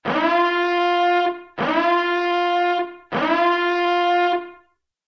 Звук ноющий тип сирены.